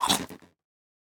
Minecraft Version Minecraft Version snapshot Latest Release | Latest Snapshot snapshot / assets / minecraft / sounds / mob / strider / eat2.ogg Compare With Compare With Latest Release | Latest Snapshot
eat2.ogg